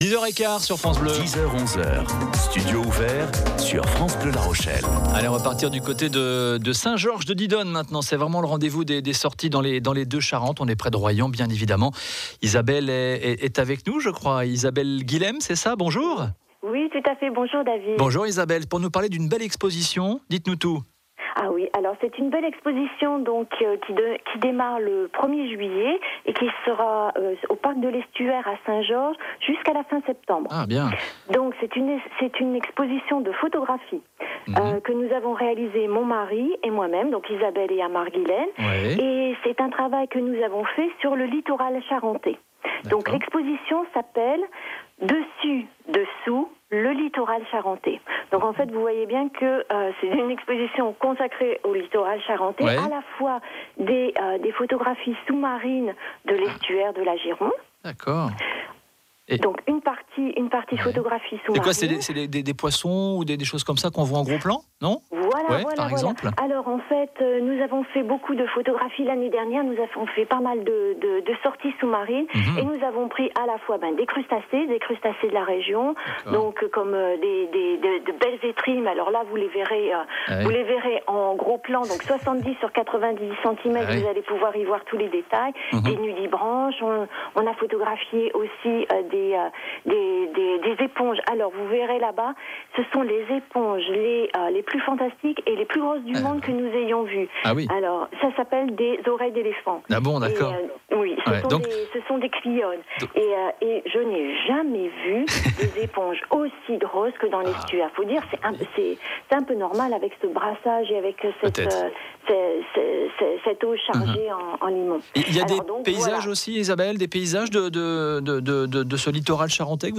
Notre interview pour radio France Bleue.